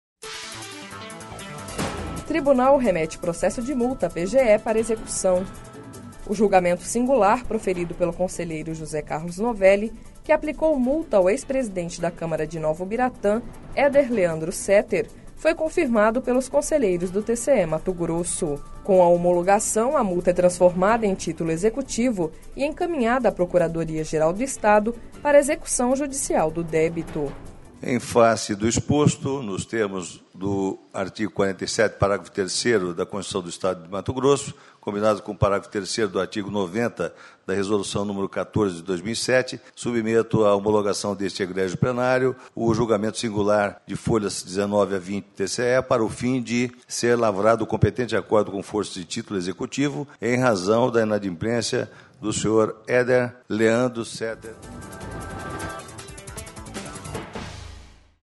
Com a homologação, a multa é transformada em título executivo e encaminhada à Procuradoria Geral do Estado (PGE) para execução judicial do débito.// Sonora: José Carlos Novelli – conselheiro do TCE-MT